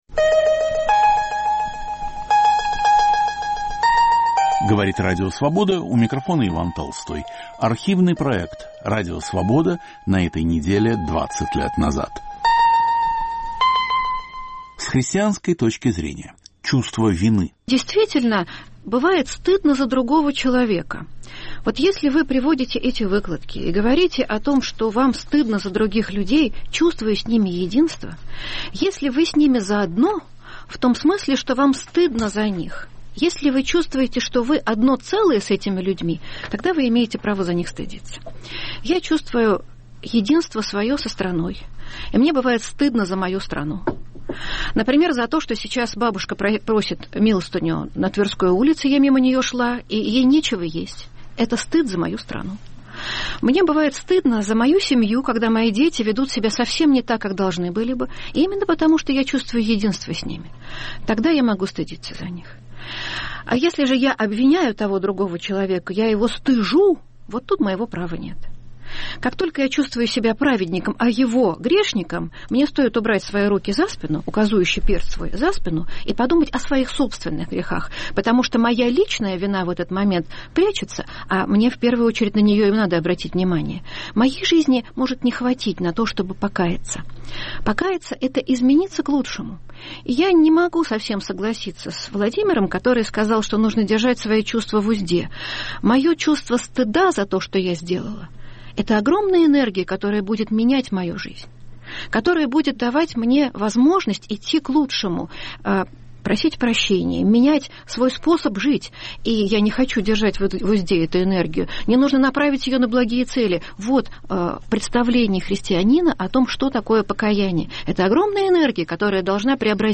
Покаяние. Отношения человека и его совести. Осмысление своих грехов. В студии